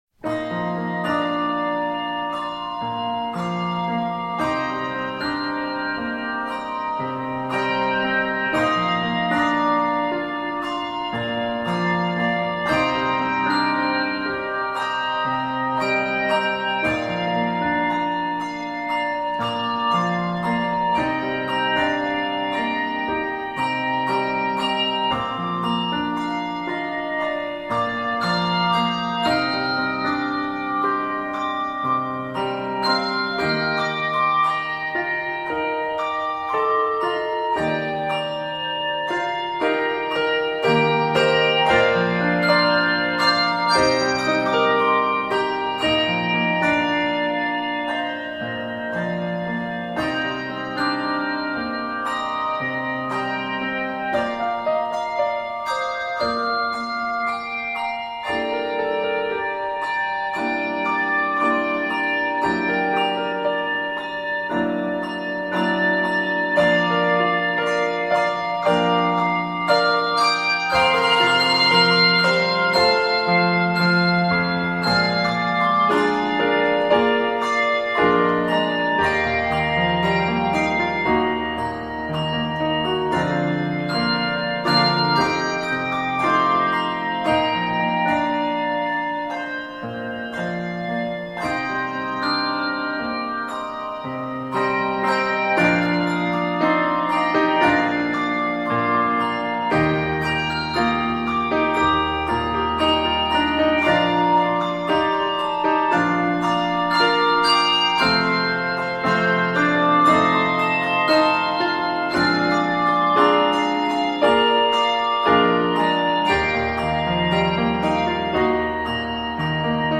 12-bell arrangement